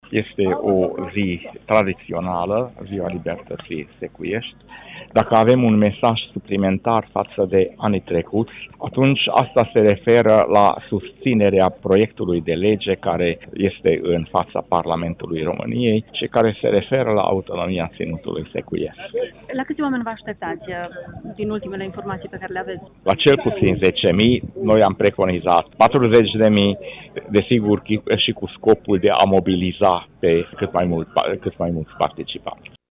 Ziua Libertăţii Secuilor este marcată la Tîrgu-Mureș.